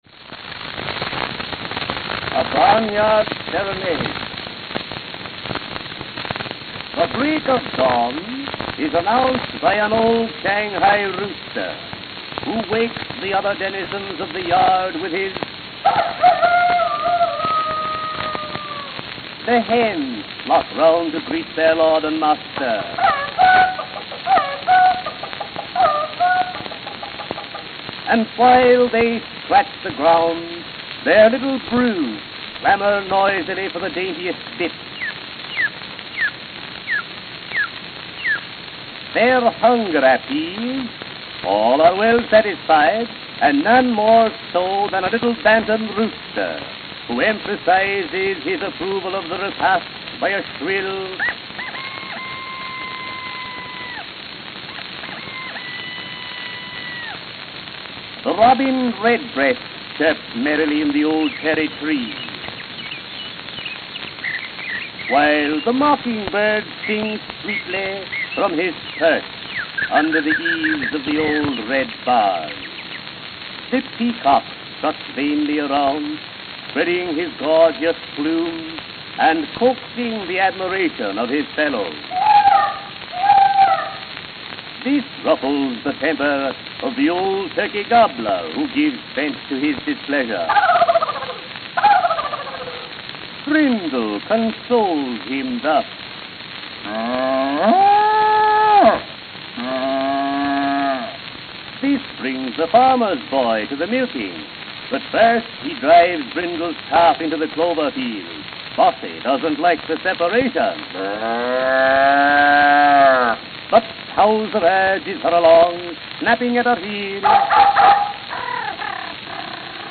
Note: Very worn.